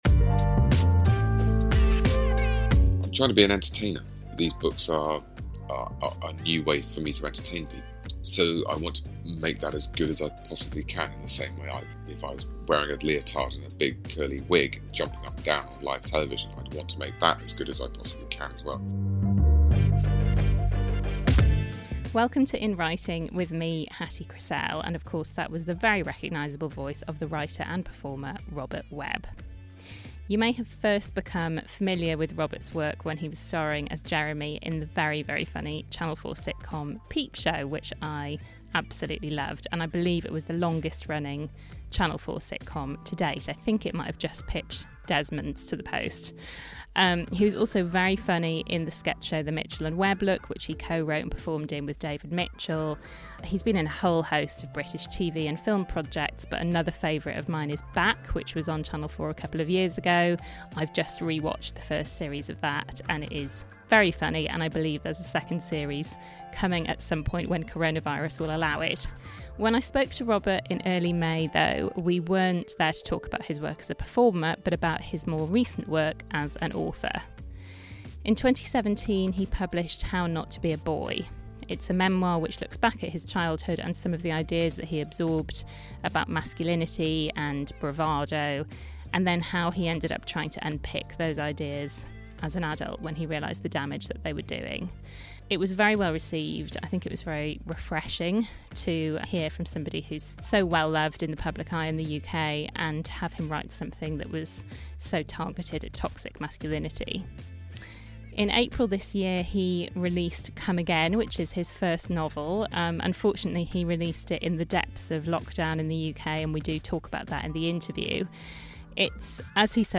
This week, from my living room in London, I speak to Robert Webb in his loft study (also in London).